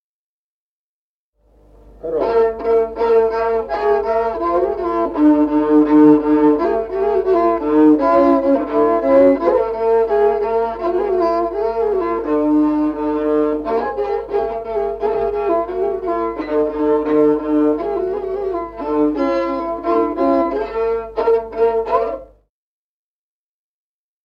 Музыкальный фольклор села Мишковка «Коробочка», партия 2-й скрипки.